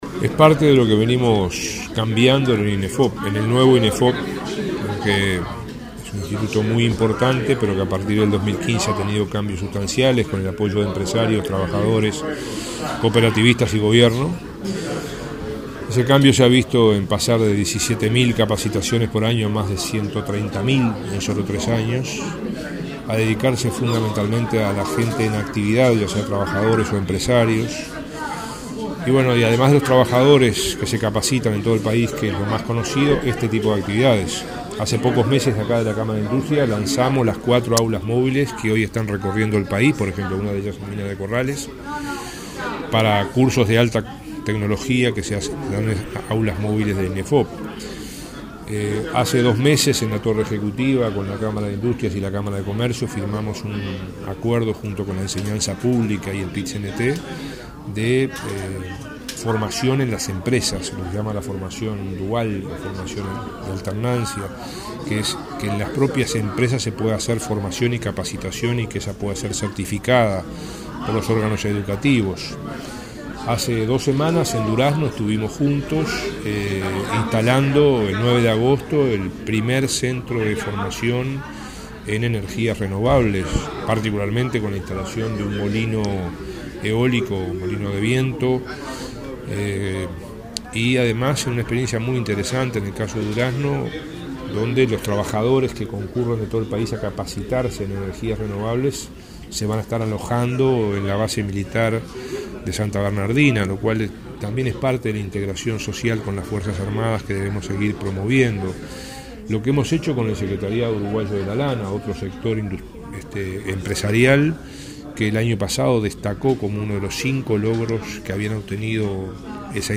“Esto es parte de un conjunto de actividades para mejorar el desarrollo productivo, industrial y empresarial de Uruguay”, señaló el ministro de Trabajo y Seguridad Social, Ernesto Murro, en el lanzamiento del proyecto Impulsa Industria, que comenzará en octubre, mediante el cual Inefop invertirá US$ 1,4 millones para apoyar a 700 empresas durante dos años.